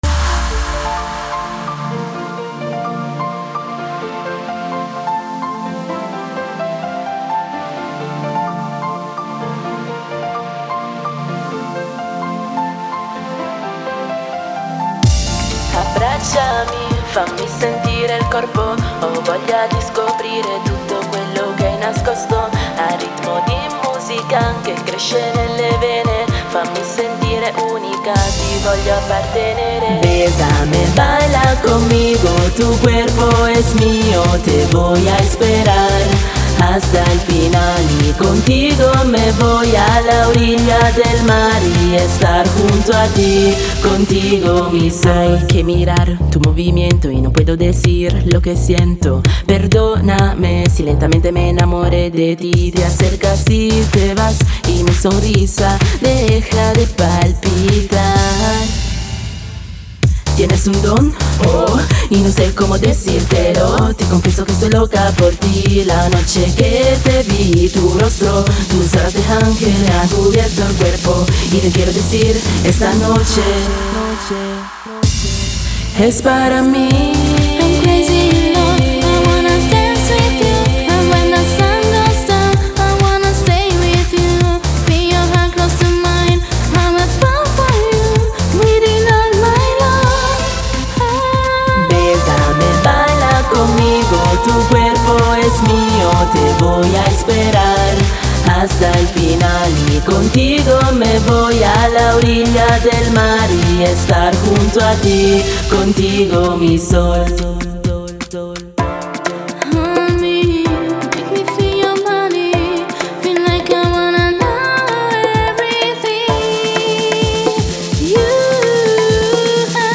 Contemporaneamente si appassiona alla musica e in particolar modo al 'Rap Melodico'/'Pop'/'Rap'.